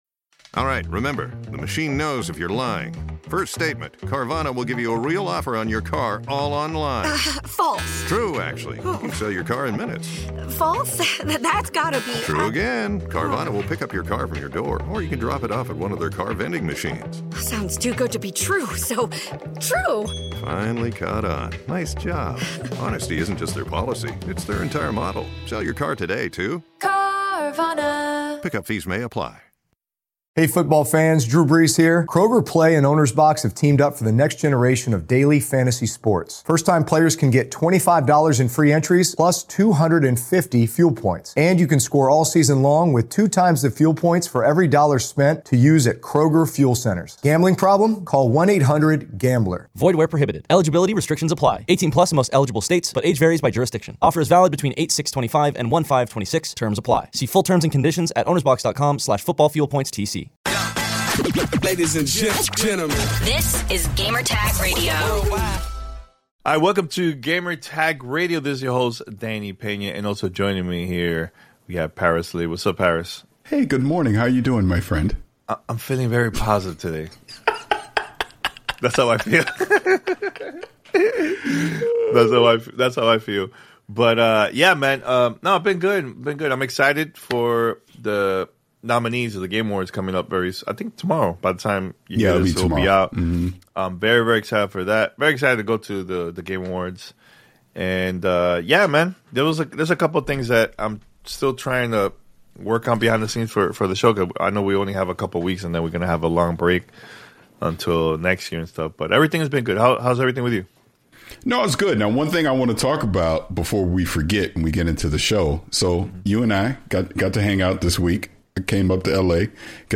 Tune in to hear their thoughts on the specs, pricing, and what this means for the future of gaming in the living room. Whether you're a tech enthusiast or a casual gamer, this conversation is packed with insights and lively debate.